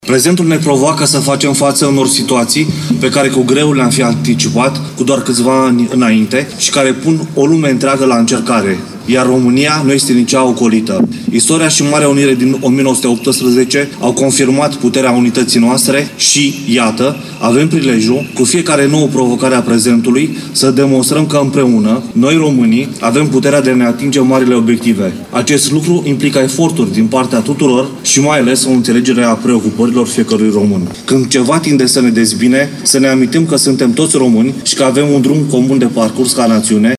Ziua Națională a fost marcată în municipiul Suceava printr-o ceremonie festivă și o paradă militară pe bulevardul Ana Ipătescu.
După o slujbă religioasă, prefectul ALEXANDRU MOLDOVAN a prezentat mesajul Guvernului, potrivit căruia “Marea Unire a avut loc după nenumărate sacrificii umane și materiale”.